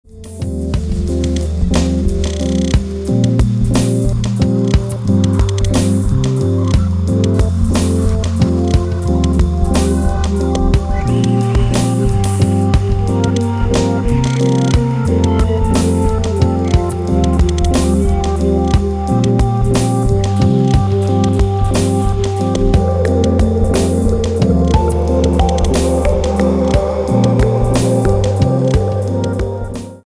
File Under: idm